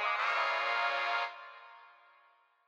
GFunk III.wav